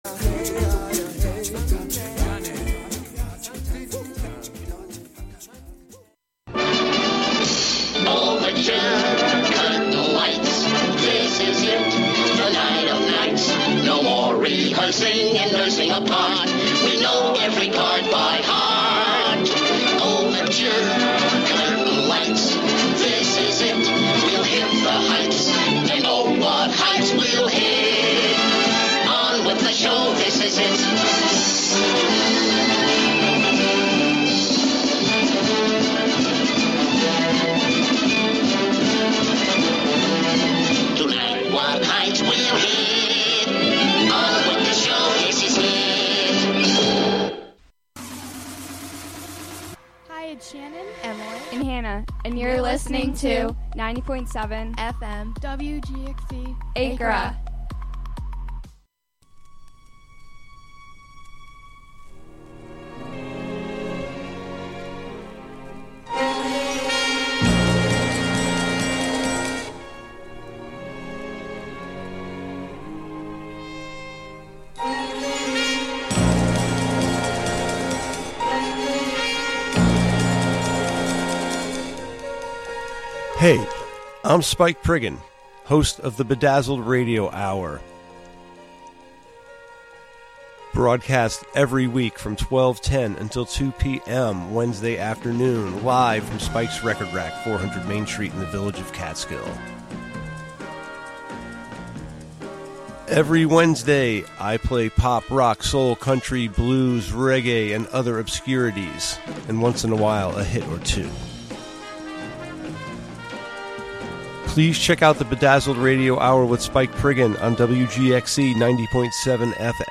broadcast live from WGXC's Catskill studio.